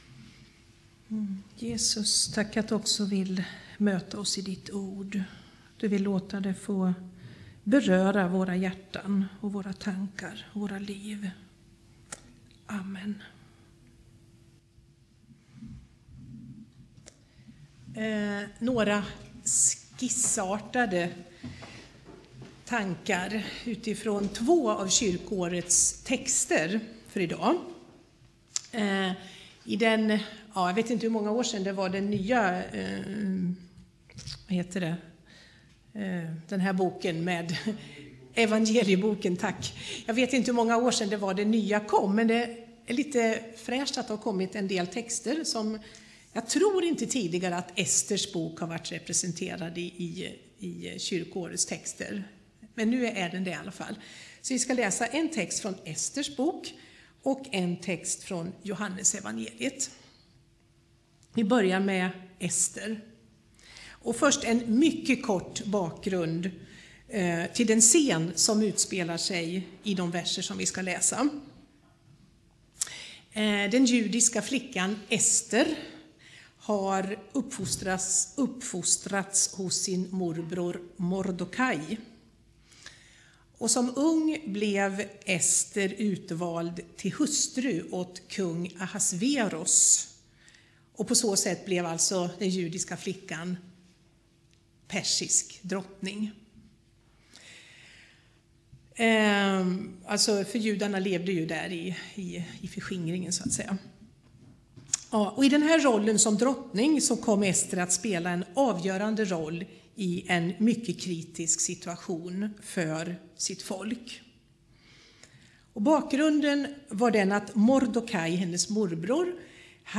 2010-02-14 Predikan av